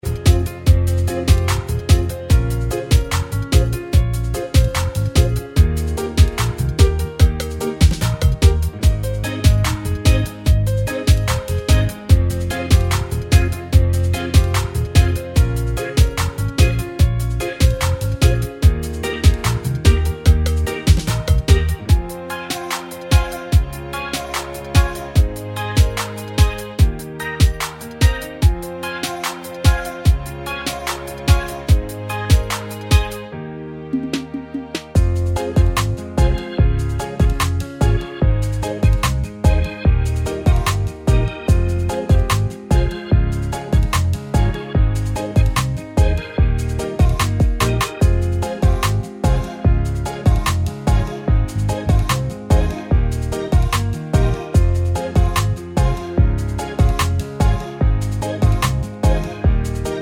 Reggae Version with No Backing Vocals Reggae 3:54 Buy £1.50